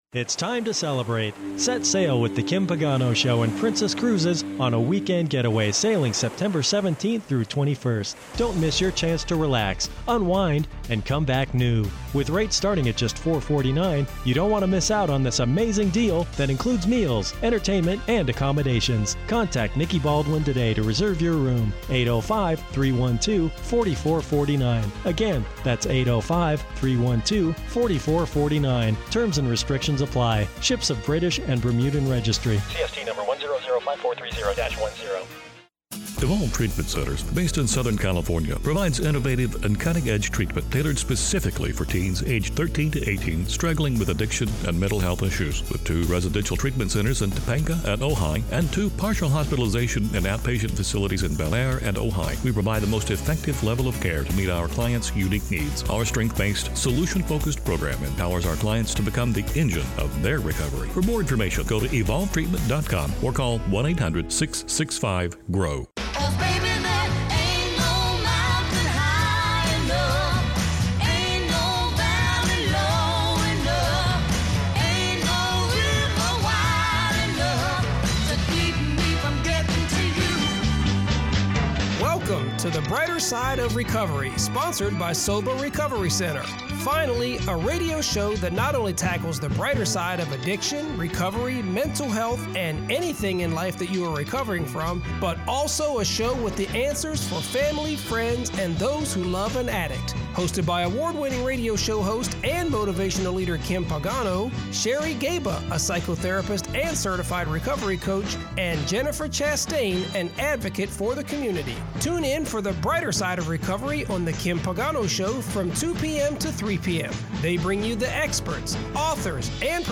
Finally, a radio show that not only tackles the brighter side of addiction, recovery, and mental health, but a show with the answers for the family, friends, and those who love an addict.